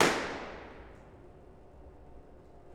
Balloon Pop 2